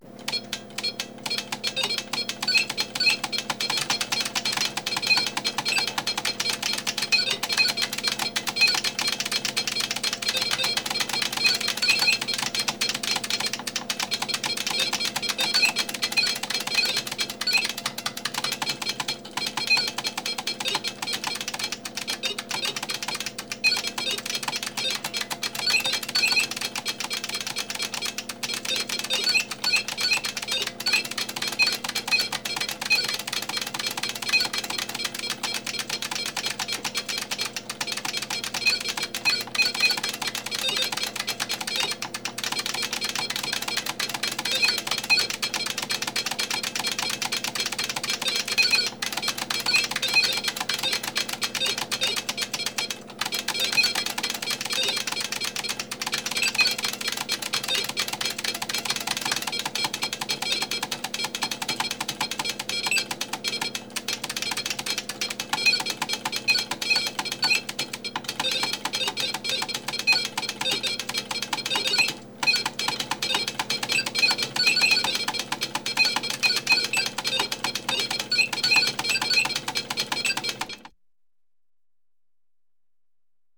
ambience
Computer Video Game - Punching on Keyboard and Beeps 2